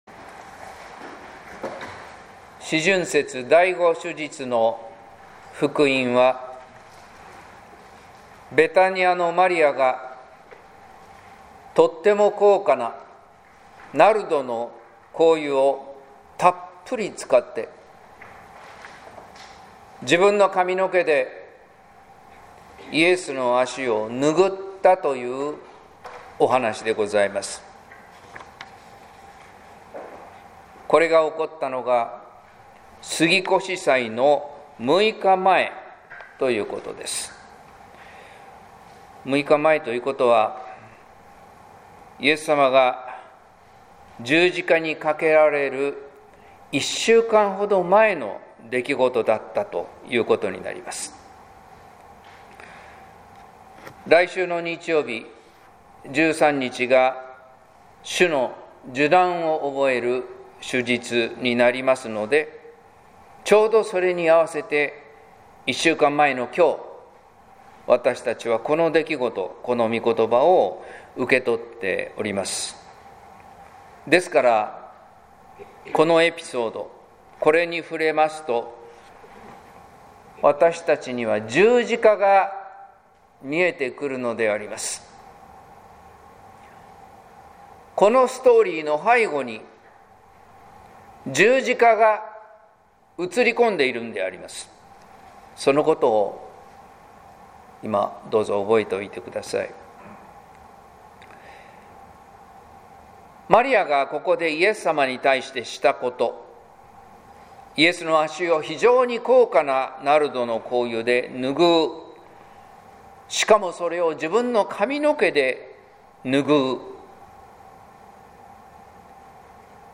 説教「十字架から見える救い」（音声版） | 日本福音ルーテル市ヶ谷教会
説教「十字架から見える救い」（音声版）